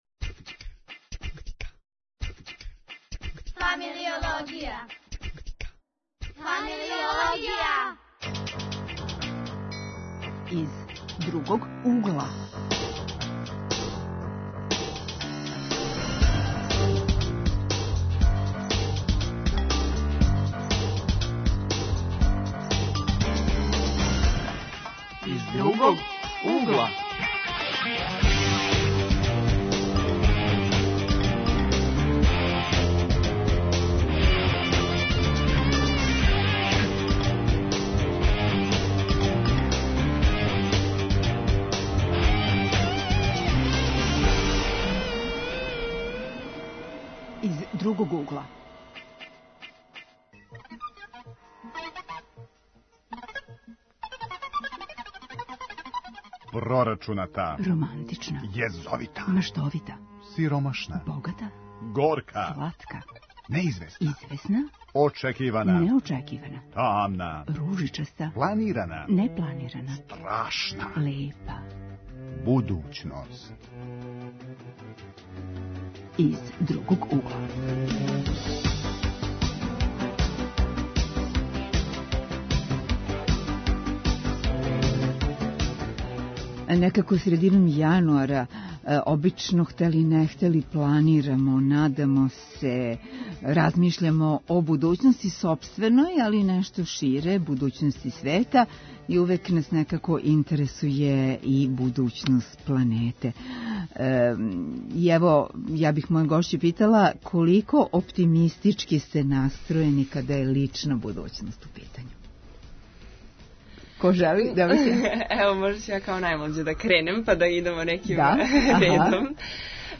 Гости у студију су студенти.